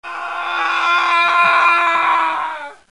Suono effetto - wav "Aaaagh"
Urlo umano straziante, come di dolore.